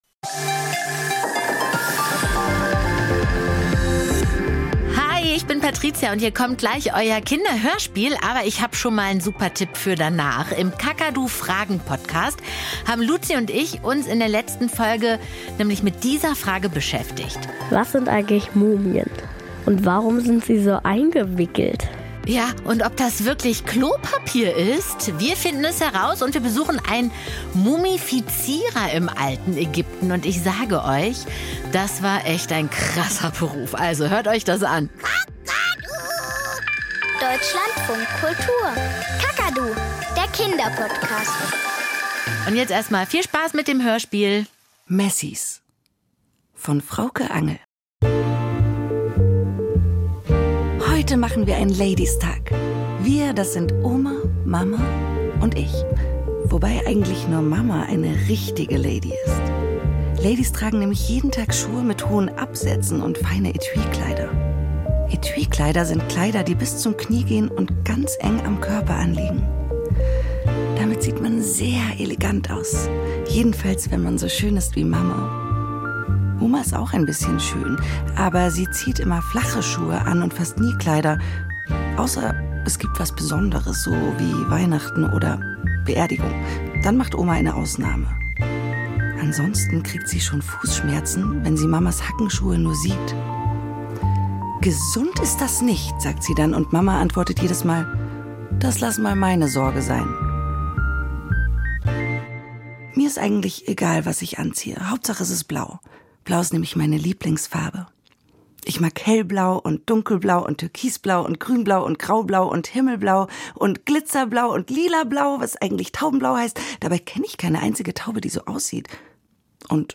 Kinderhörspiel und Geschichten - Messie